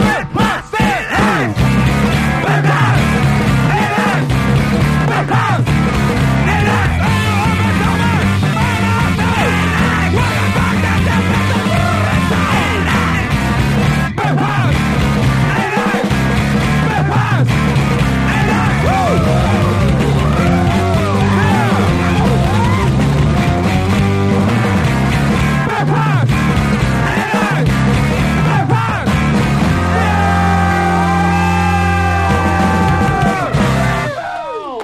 625 THRASHCORE
BASS
DRUMS
GUITAR
VOCALS